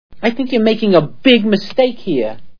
Manhattan Movie Sound Bites